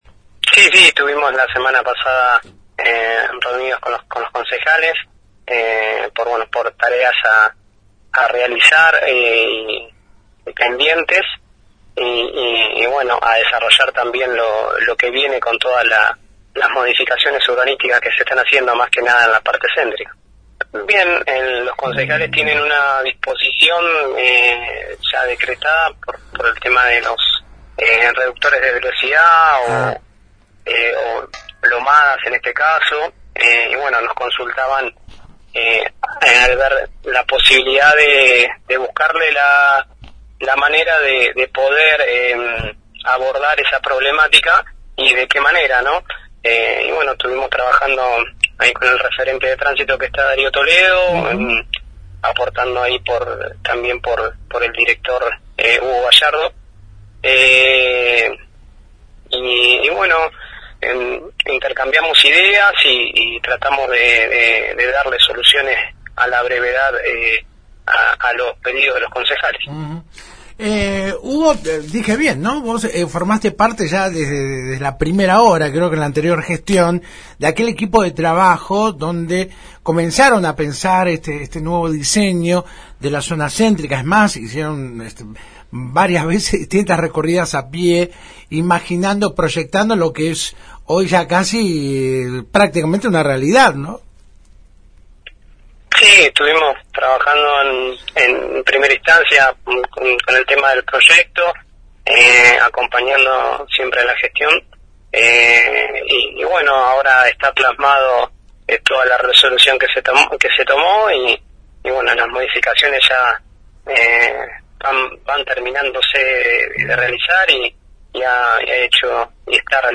El Coordinador General de Infraestructura Vial, Hugo Vázquez, se refirió este lunes en FM Alpha al nuevo Plan de Ordenamiento Urbano que el viernes pasado sumó un nuevo capítulo con el doble sentido de circulación en Avda. Gral. Paz entre Avda. San Martín y Avda. Carmen como así también la reunión mantenida con los integrantes de la Comisión de Legislación y Reglamentación del HCD local.